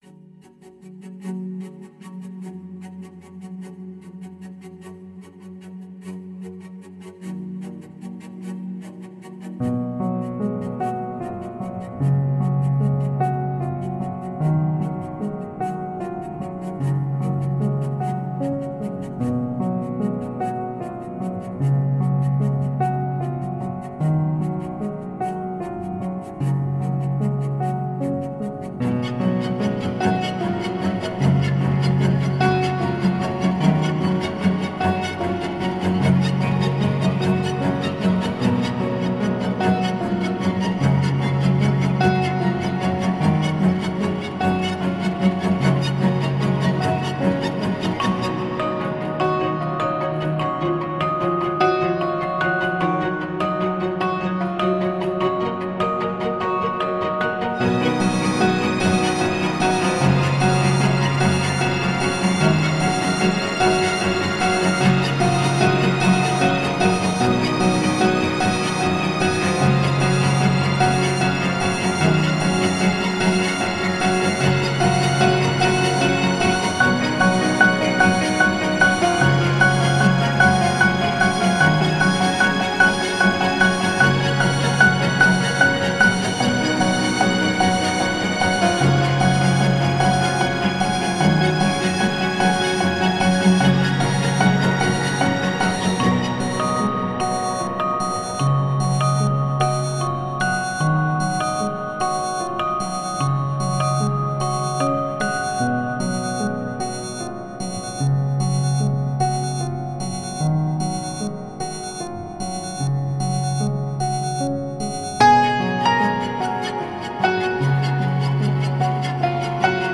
In Reason it sounds fine, as a WAV it sounds clippy.